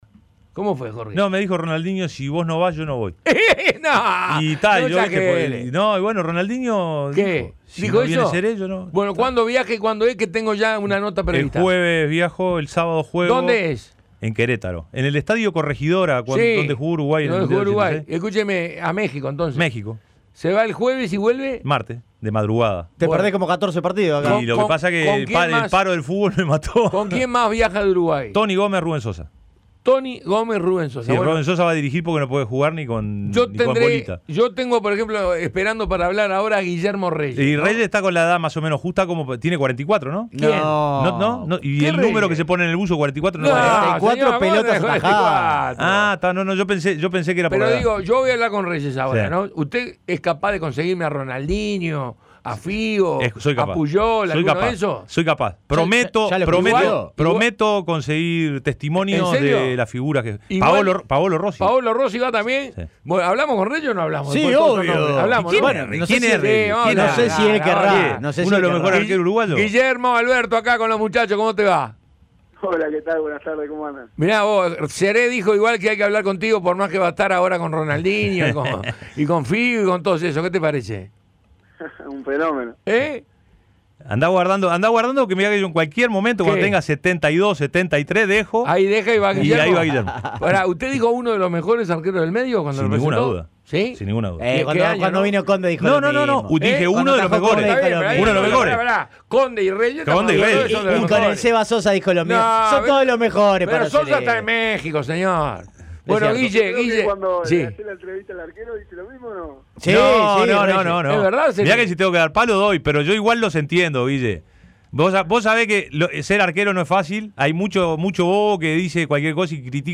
Asimismo, se mostró con confianza para lo que viene. Entrevista completa.